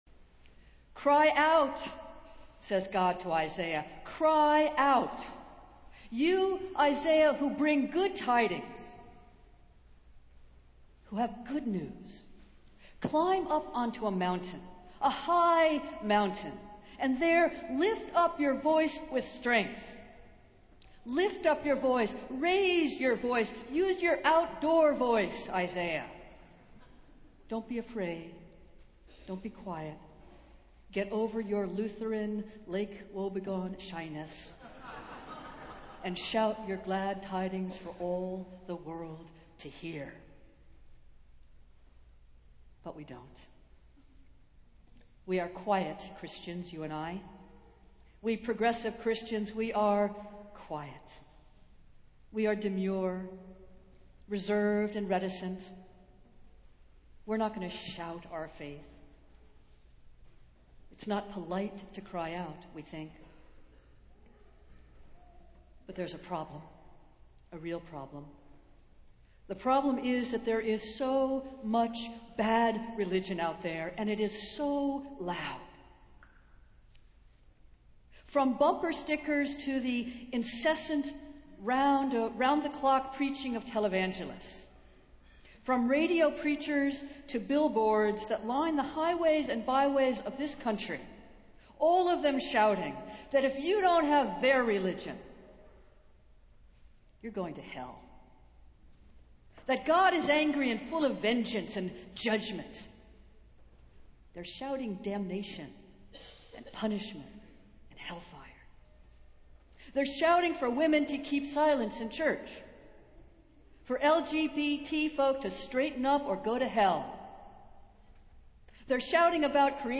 Festival Worship - Second Sunday in Advent